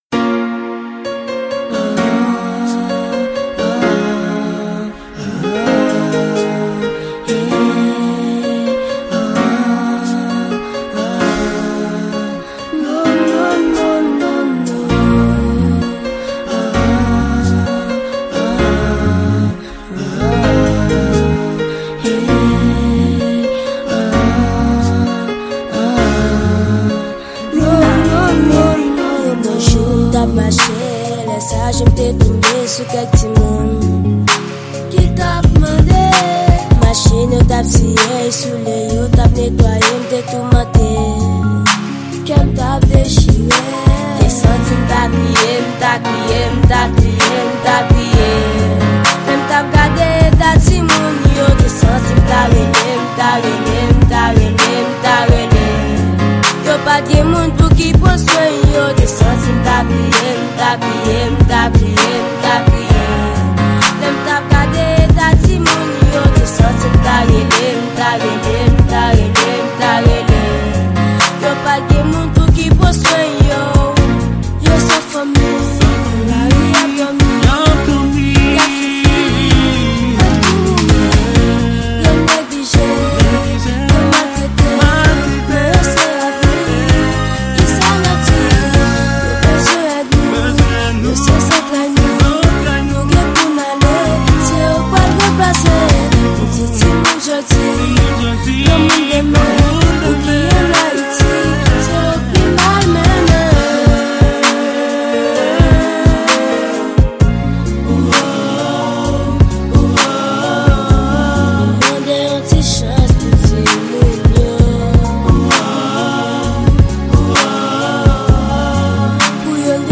Genre:World.